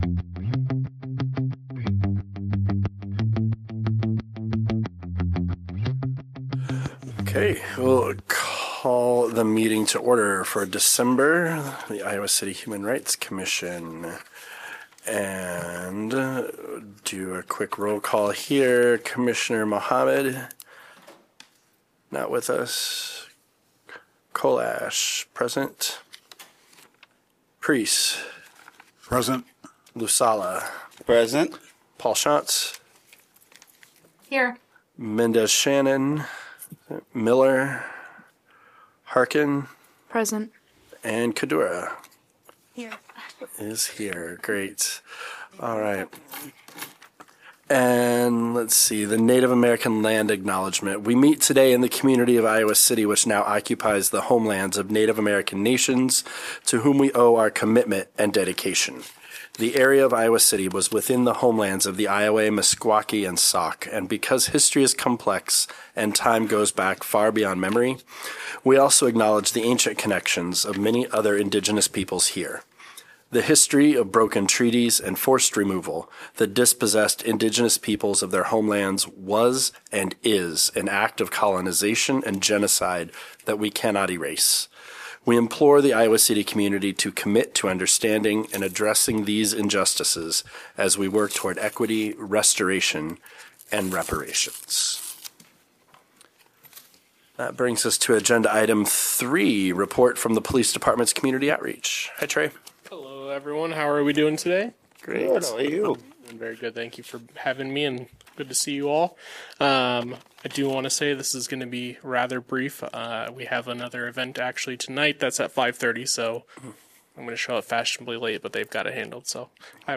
Regular monthly meeting of the Human Rights Commission.